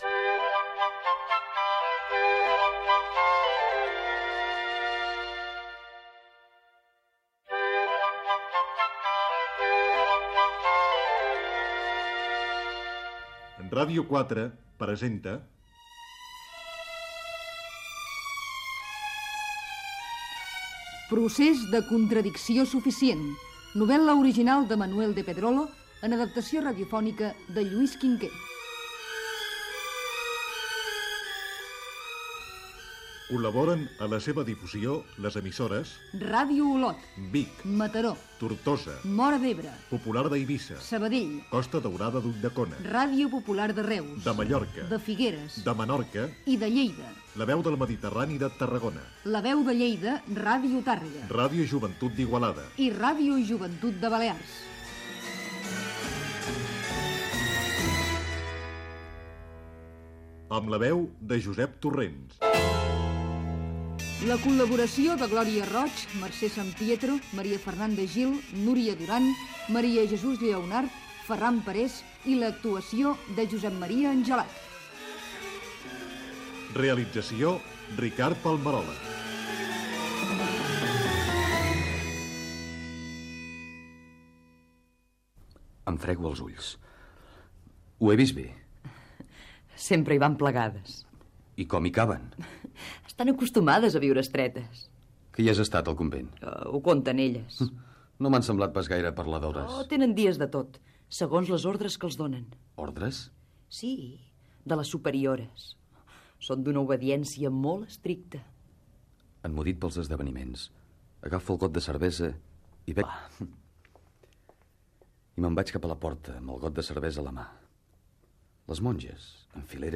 Segona part de l'adaptació radiofònica
Sintonia de la ràdio, careta del programa amb les emissores que l'emeten i el repartiment
Ficció